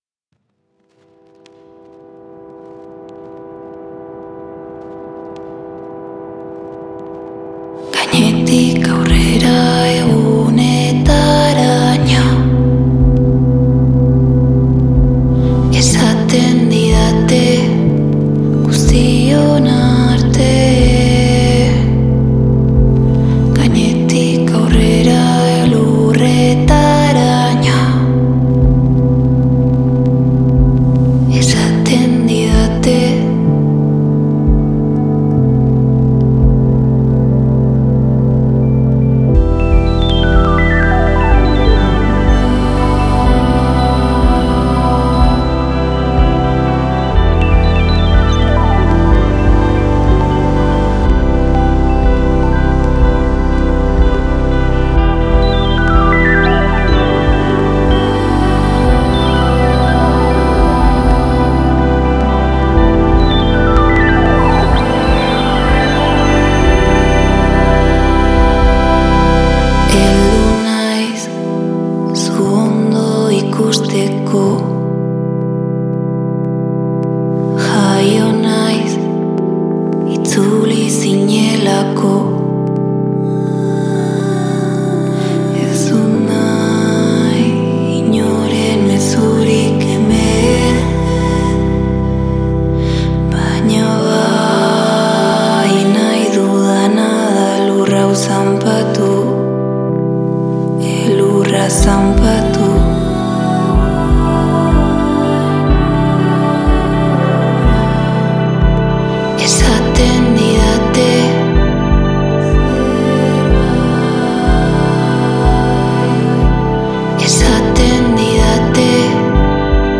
Genres: Experimental, Dreamgaze, Ambient